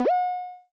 {Perc} pick up 2.wav